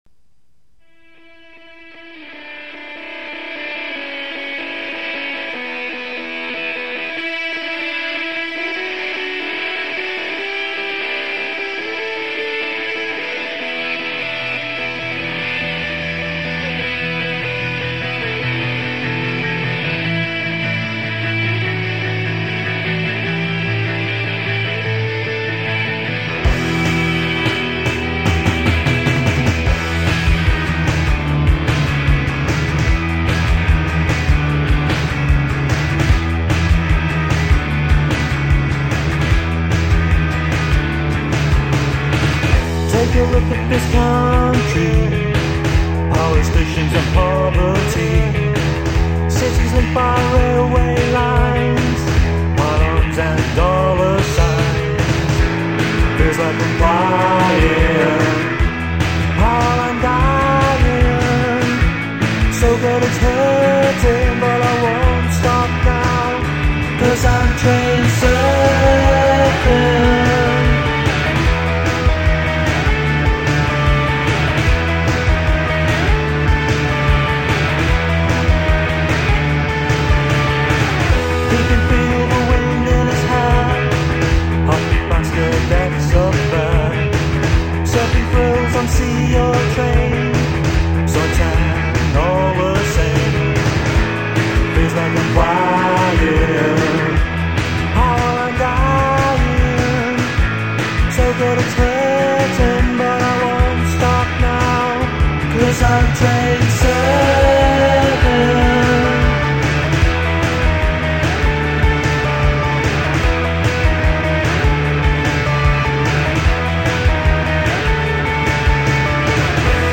recorded some demos in the early 90s
the band was mainly a three-piece
vocals and guitar
bass